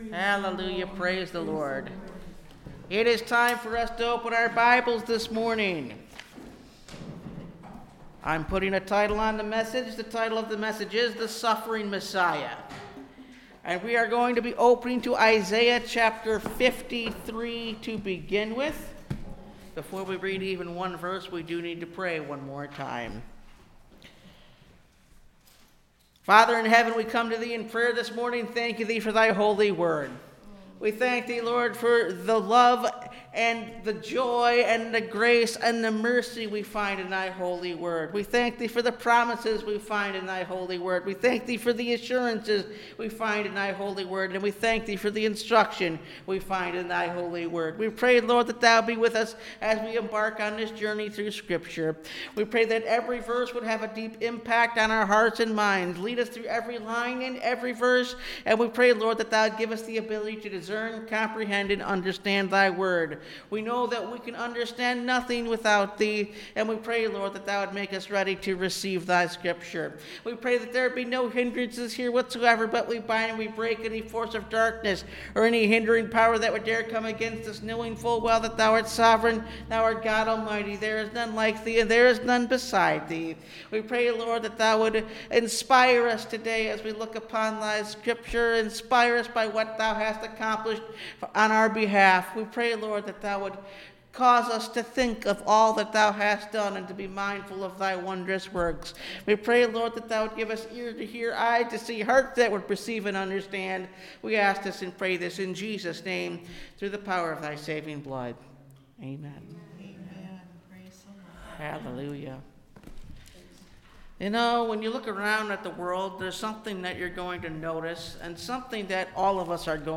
The Suffering Messiah (Message Audio) – Last Trumpet Ministries – Truth Tabernacle – Sermon Library